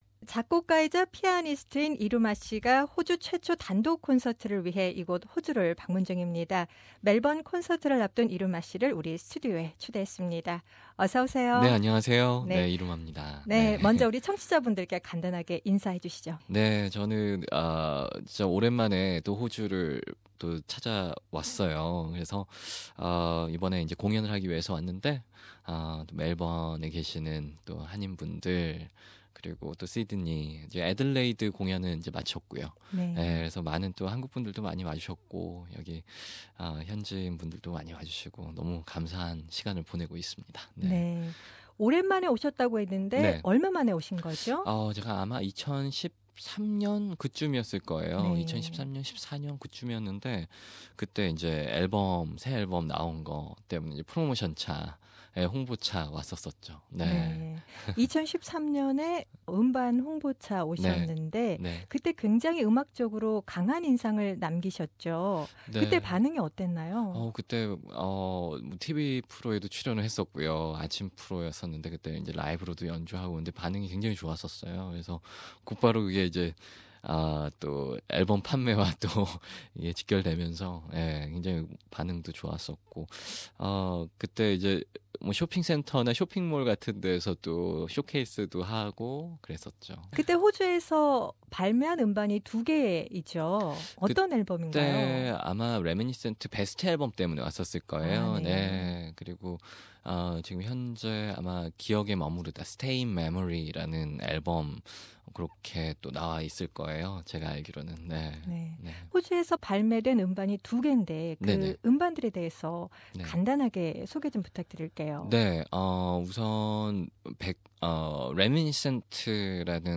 In a special interview with SBS Korean Program, he talks about his life, music and, of course, concerts.